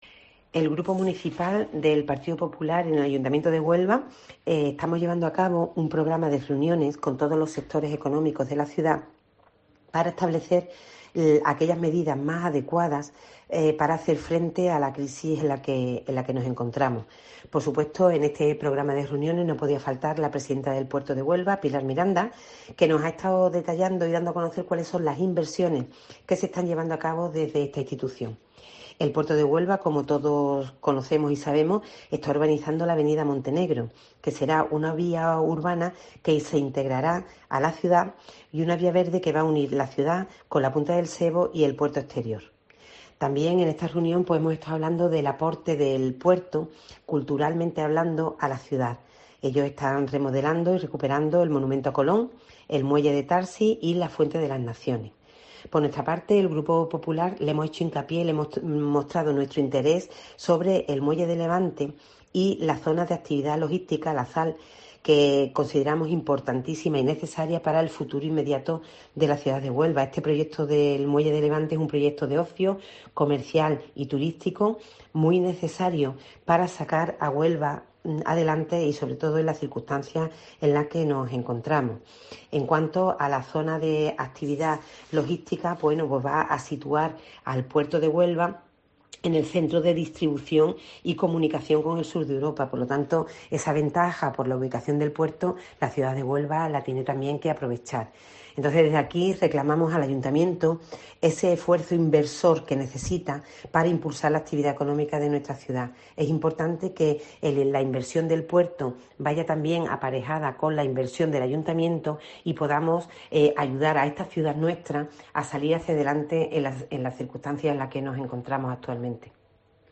Pilar Marín, presidenta del Grupo Municipal del Partido Popular